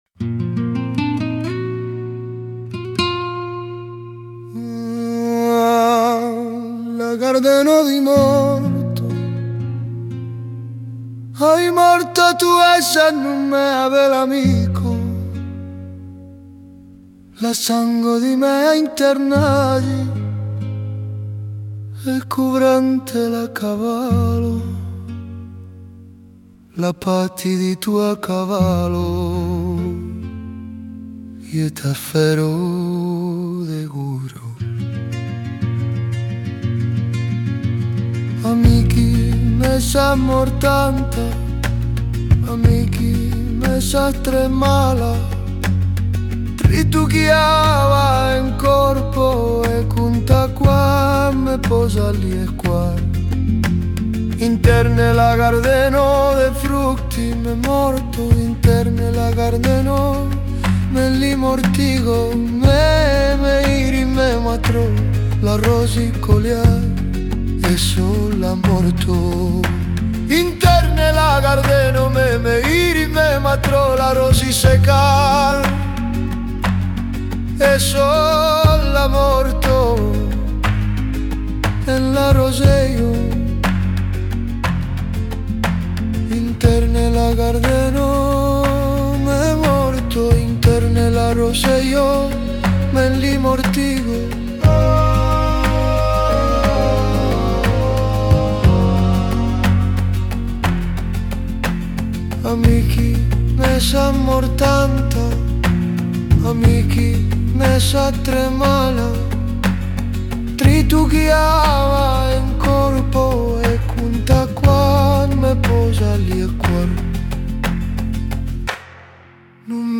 kansoni flamenco